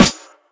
Snares
snrdum4.wav